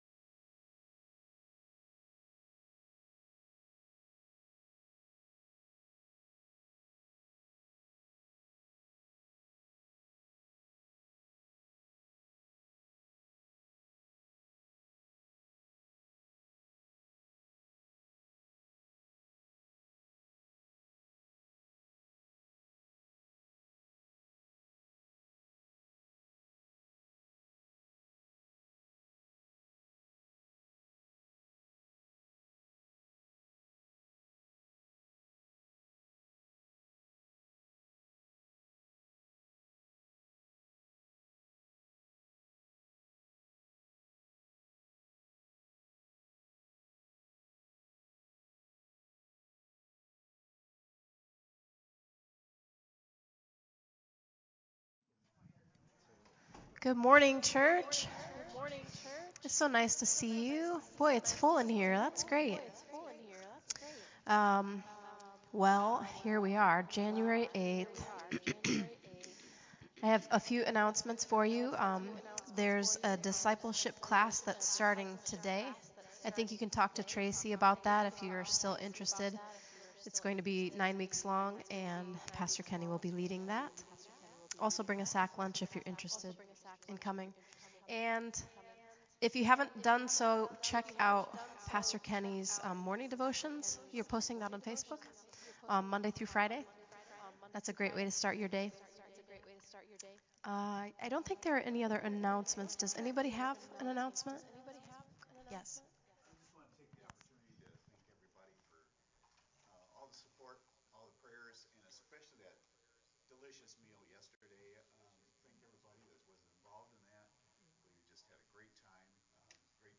Worship from January 8 2023
Praise Worship